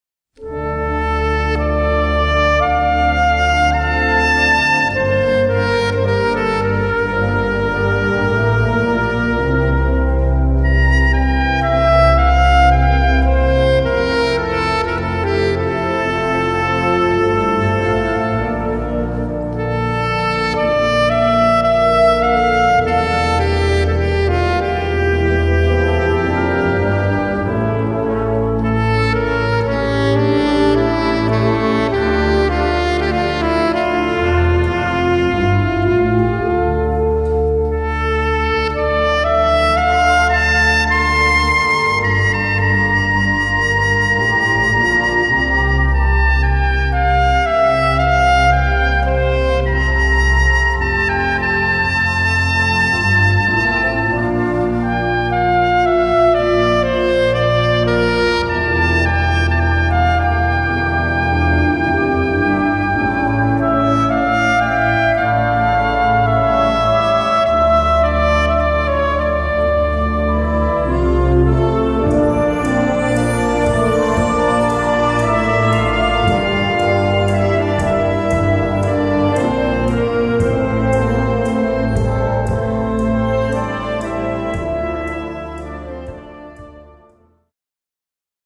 Gattung: Solo für Sopransaxophon oder English Horn
Besetzung: Blasorchester
solo for soprano saxophone or English horn